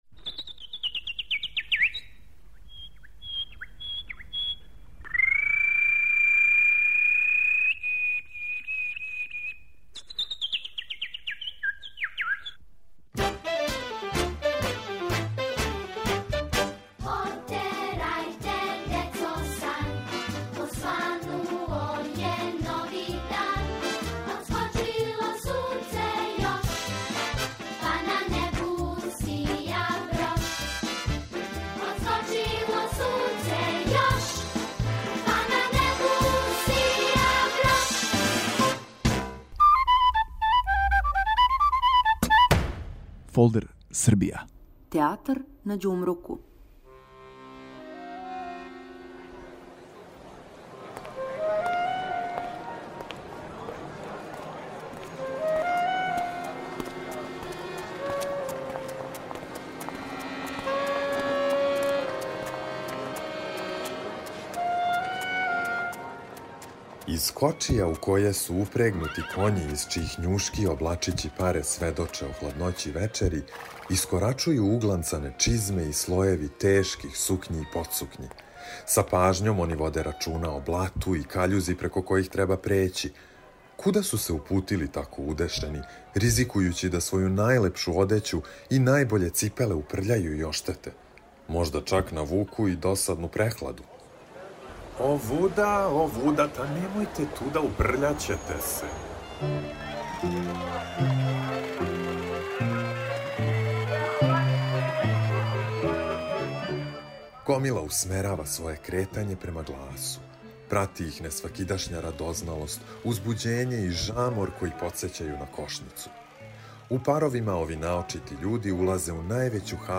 У серијалу Фолдер Србија послушајте причу о чувеном Театру на Ђумруку, ослушните шушкање тканина свечаних хаљина и лепет лепеза давне 1841. године.